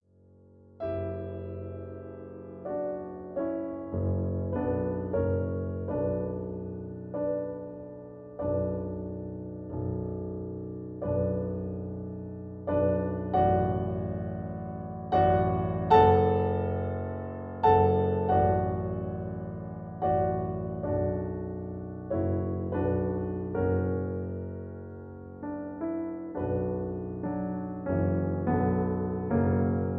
In B minor. Piano Accompaniment